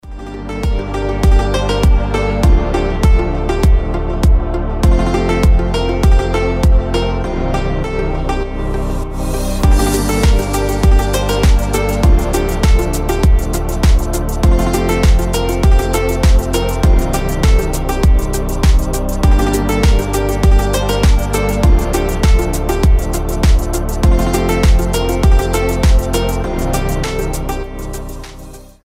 • Качество: 320, Stereo
красивые
deep house
мелодичные
без слов
этнические